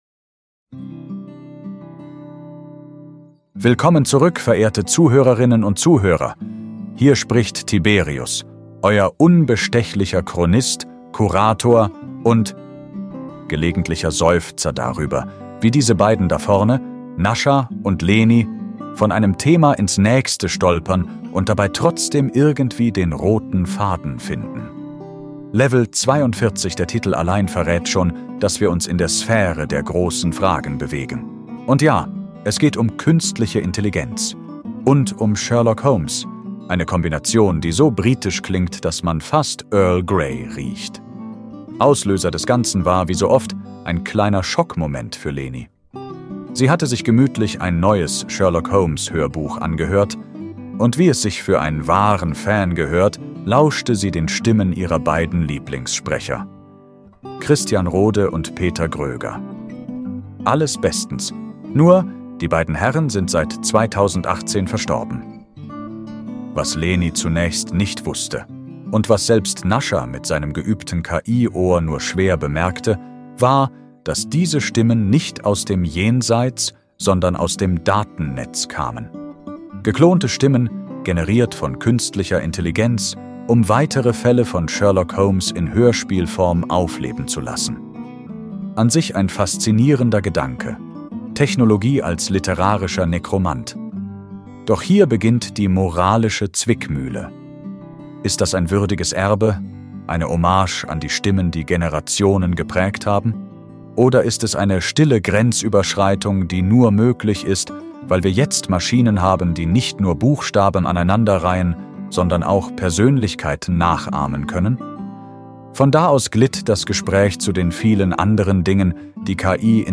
Ein Gespräch zwischen Faszination und Vorsicht, zwischen